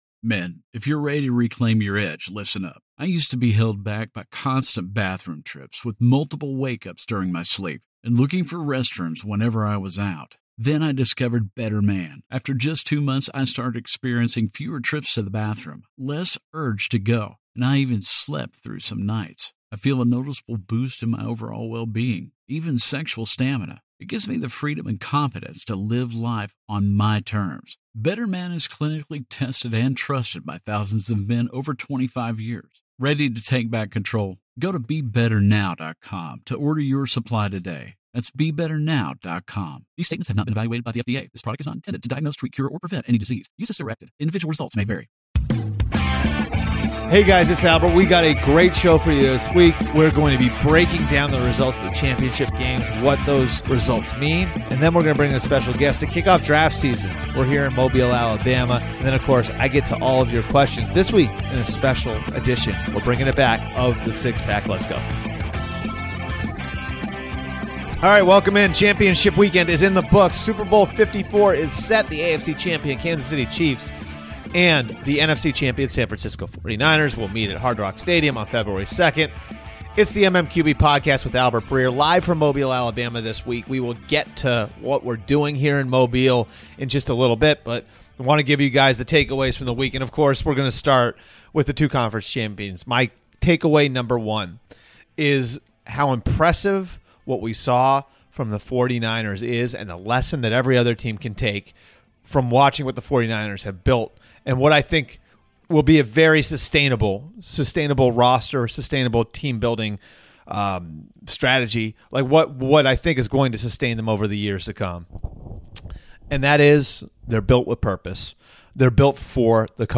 Live from Mobile, Alabama this week.